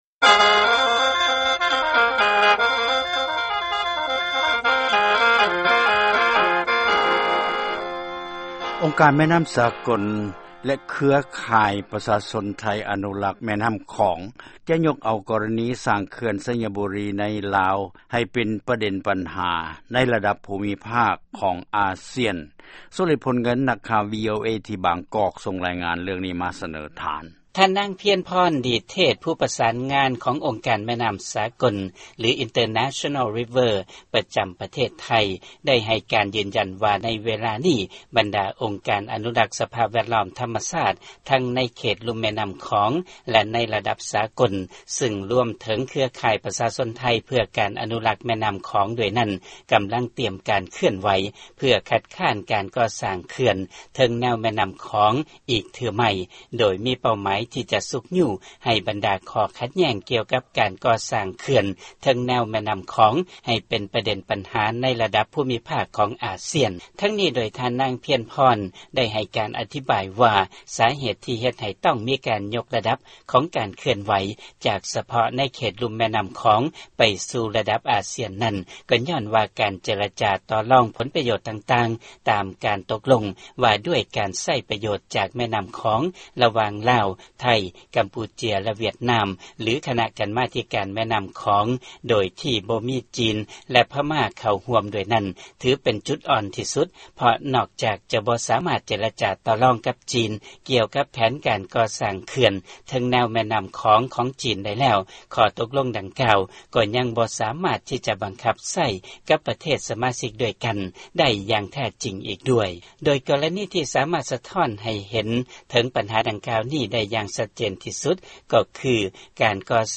ຟັງລາຍງານເລື້ອງເຂື່ອນໄຊຍະບູລີ